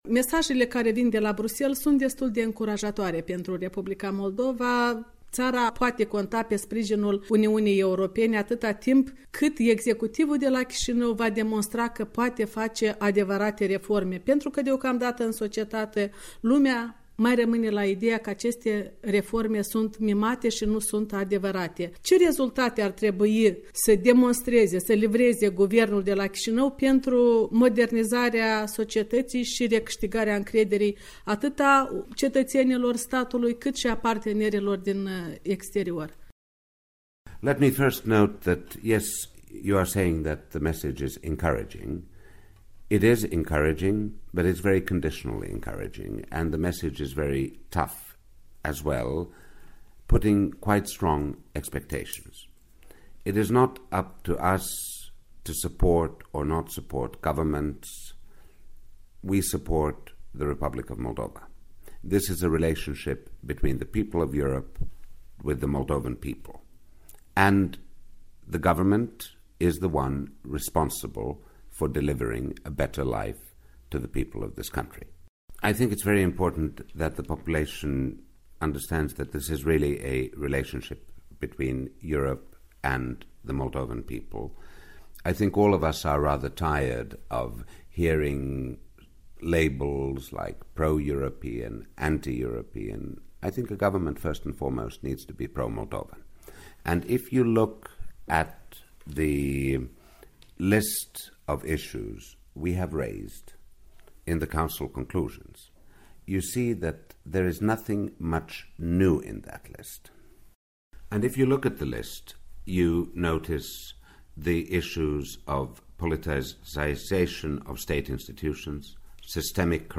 Interviu cu exclusivitate cu șeful Delegației Uniunii Europene la Chișinău.
Interviu cu Pirkka Tapiola